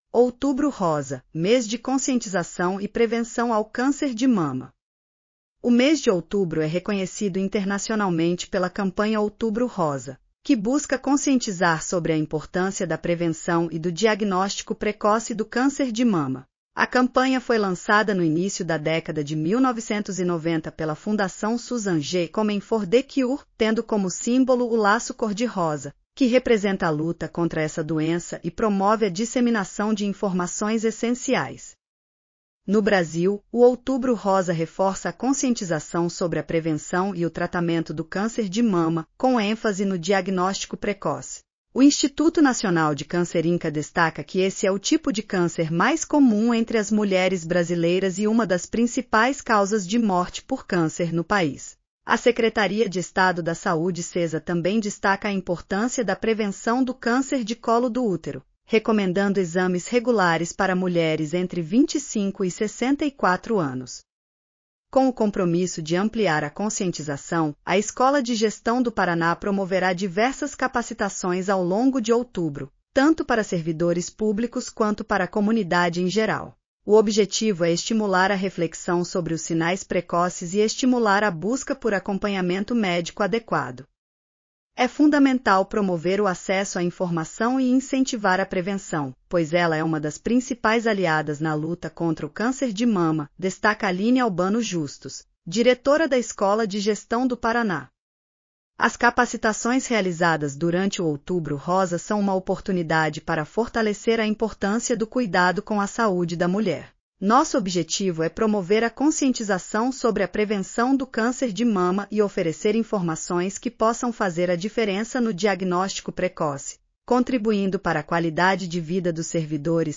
audionoticia_outubro_rosa.mp3